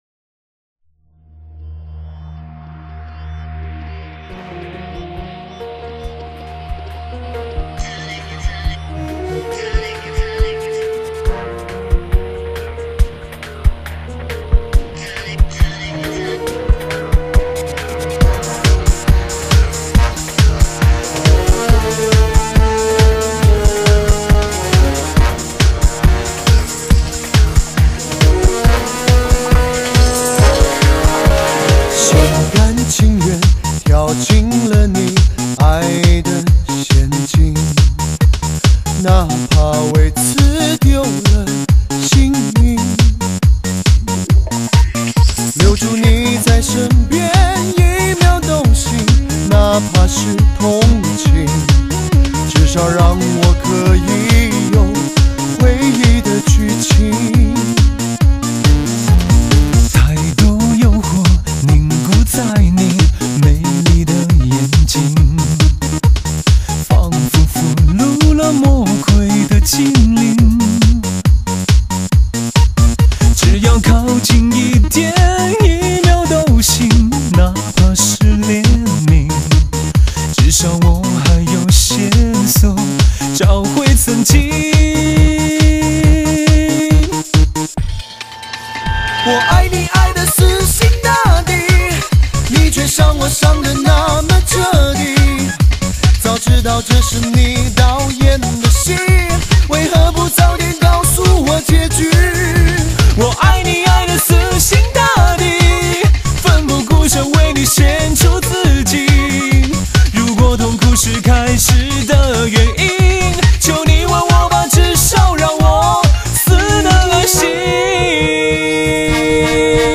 至HI舞曲 HIFI录制 最IN潮流
创新重装最受欢迎全嗨的士高发烧汽车音乐!